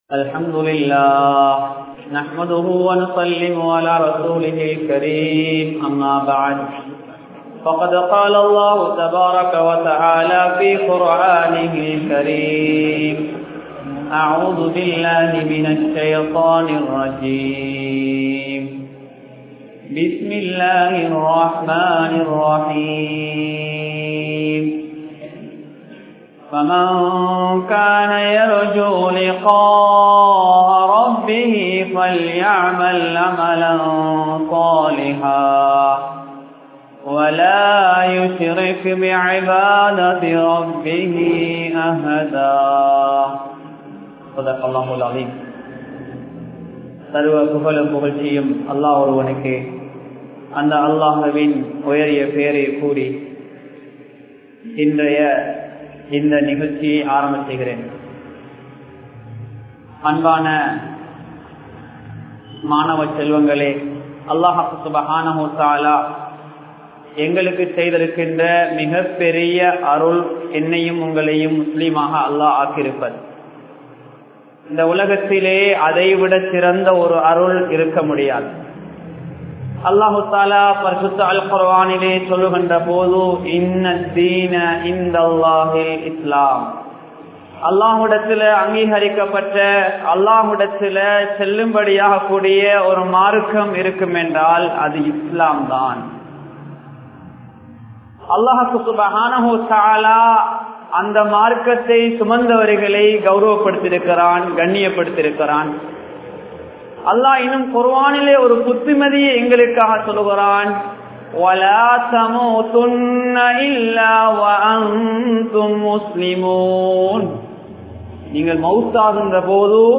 Naalaiya Thalaivarhalai Uruvaakkuvoam (நாளைய தலைவர்களை உருவாக்குவோம்) | Audio Bayans | All Ceylon Muslim Youth Community | Addalaichenai